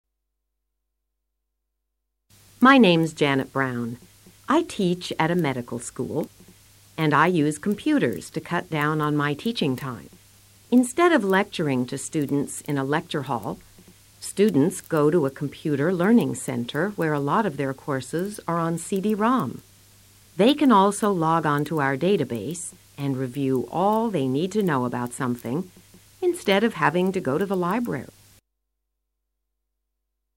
professor